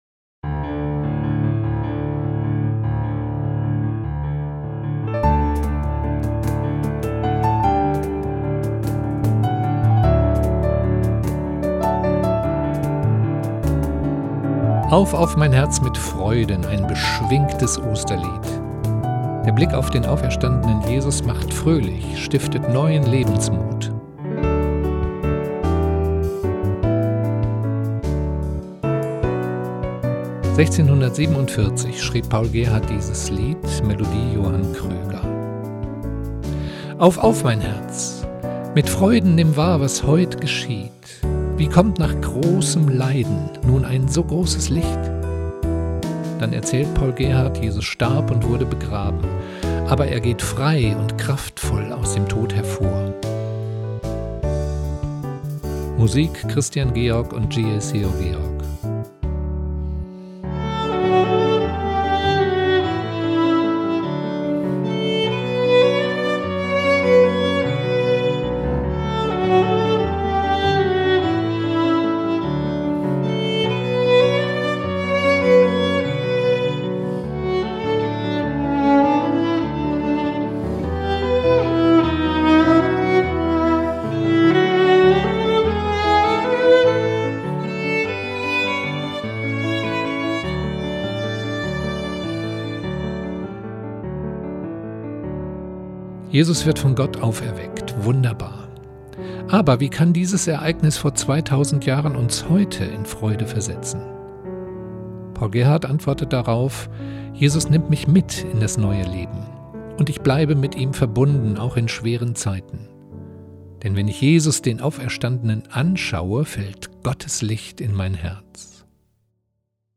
Geigen-Spiel